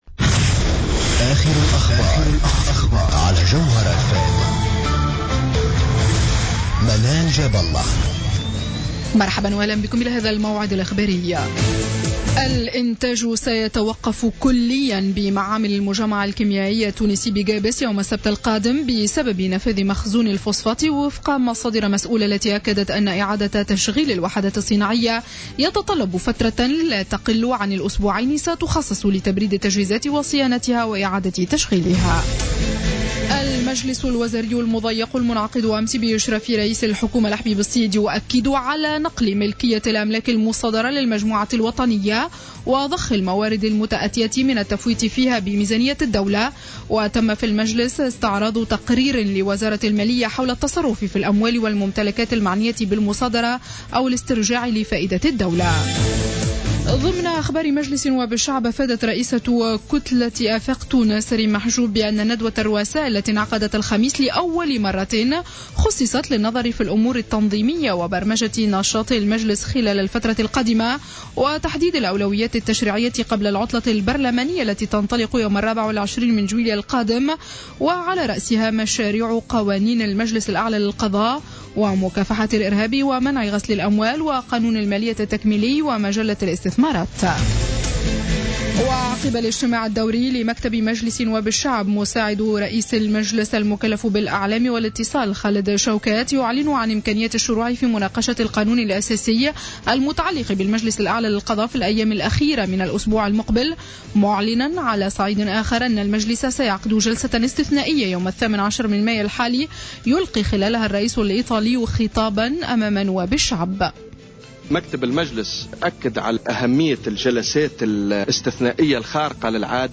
نشرة أخبار منتصف الليل ليوم الجمعة 8 ماي 2015